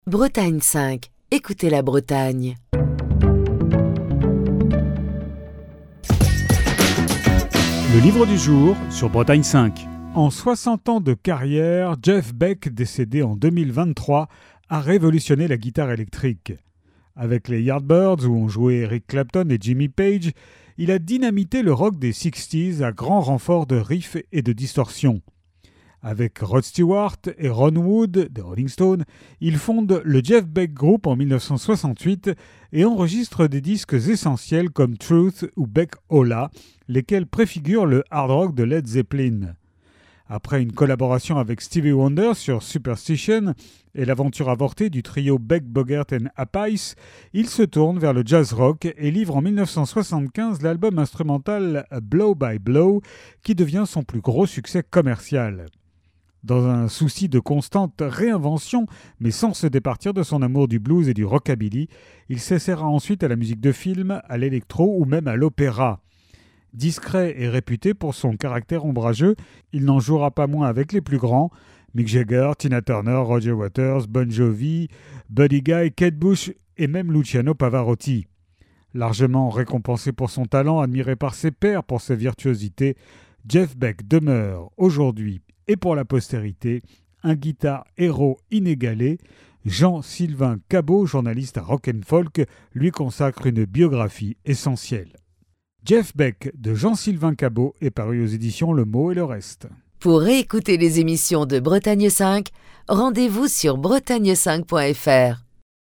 Chronique du 6 novembre 2024.